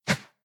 initial hit sounds
whoosh2.ogg